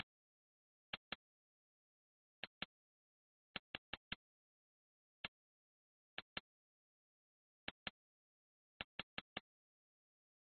hi hat 15
描述：hi hat
Tag: 镲片 hi_hat Rides